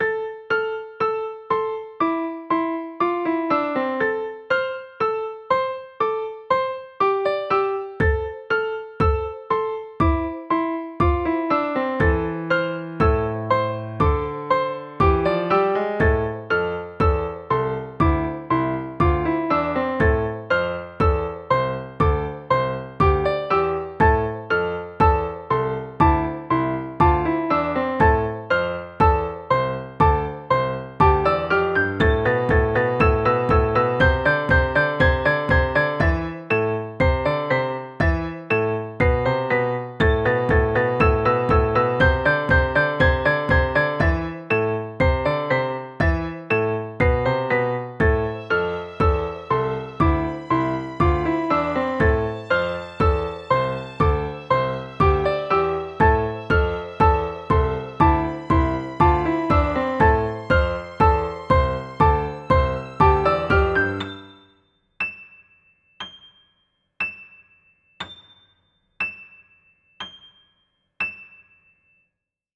不安と緊迫感のあるサウンドですねー。後半のストリングスの音良いね。